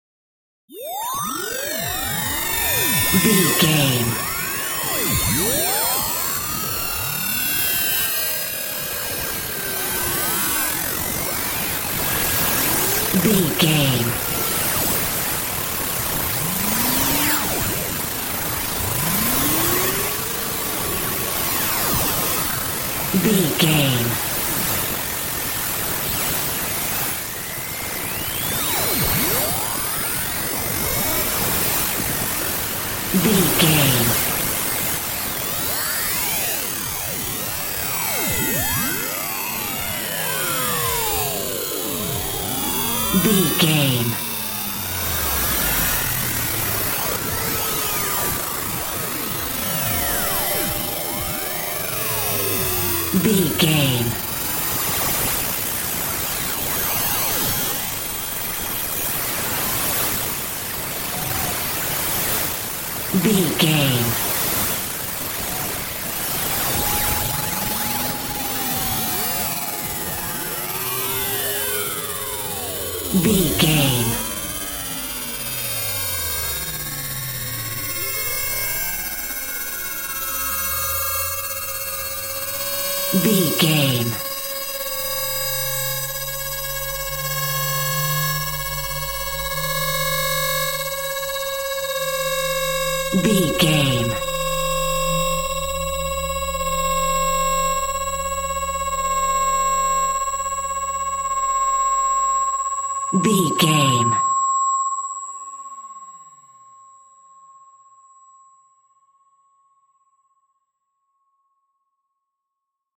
In-crescendo
Thriller
Aeolian/Minor
Slow
ominous
dramatic
eerie
Horror synth
Horror Ambience
synthesizer